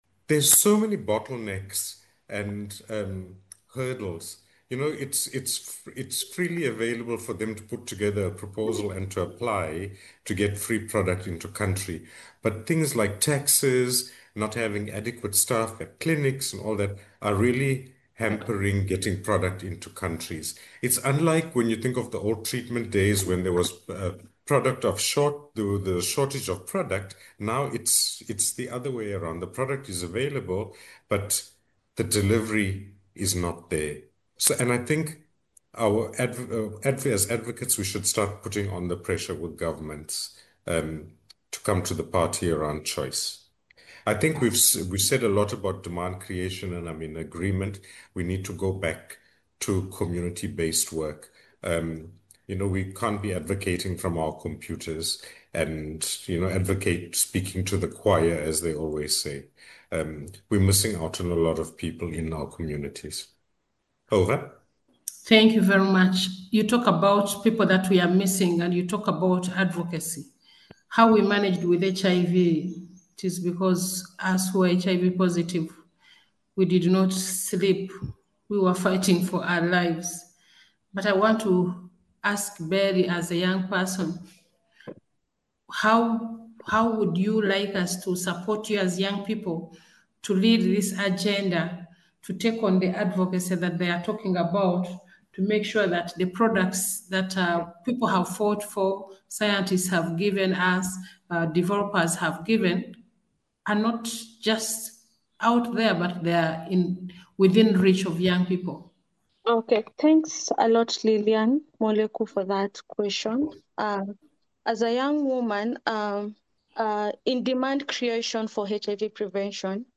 Watch this discussion on how limited access to HIV prevention puts women and girls at risk and what collective action is needed to end this form of violence.
HIV-Prevention-Webinar-SIX.mp3